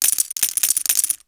Level Up Sound.wav